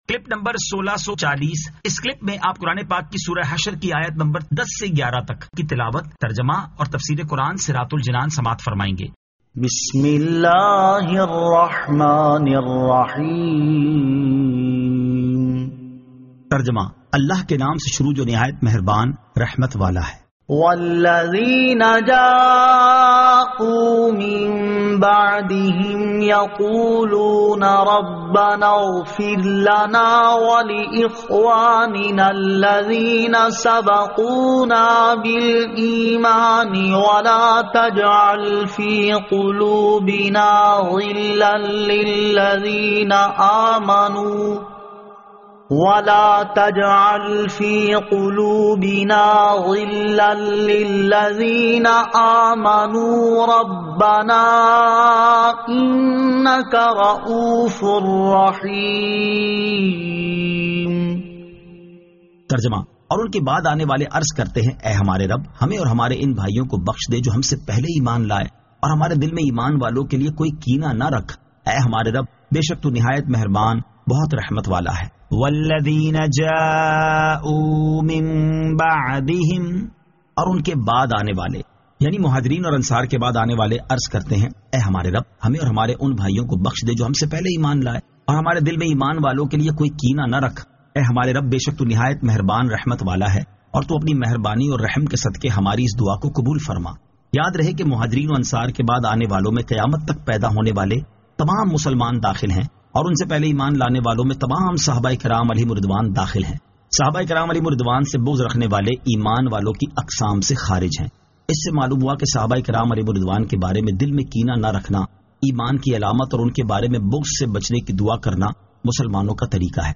Surah Al-Hashr 10 To 11 Tilawat , Tarjama , Tafseer
2024 MP3 MP4 MP4 Share سُوَّرۃُ الحَشَرٗ آیت 10 تا 11 تلاوت ، ترجمہ ، تفسیر ۔